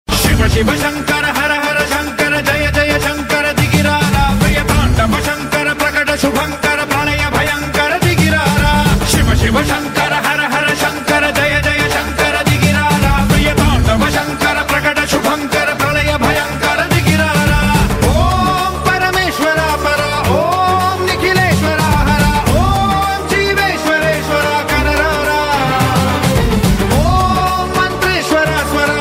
CategoryDevotional Ringtones